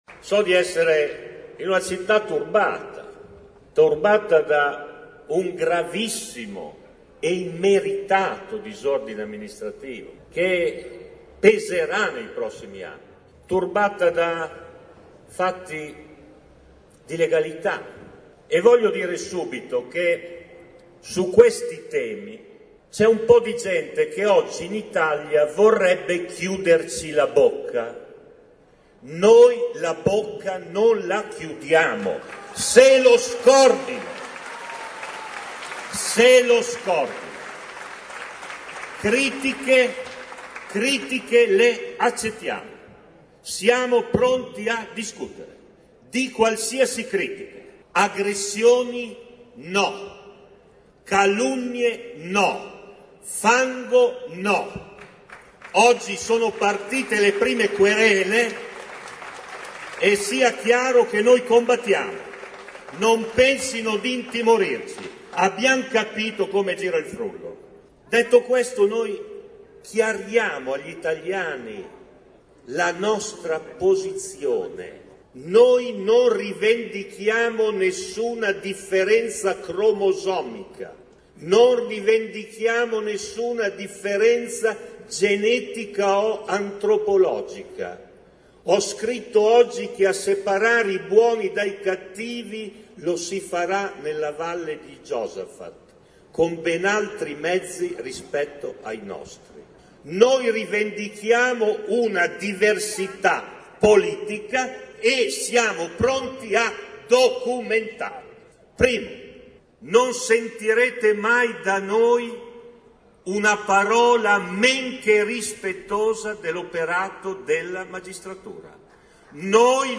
In piazza molta gente, tanti i militanti convinti del partito che hanno ascoltato un discorso dove si è accennato all’inchiesta di Monza e si è attaccato Tremonti e la manovra.
Ascolta Bersani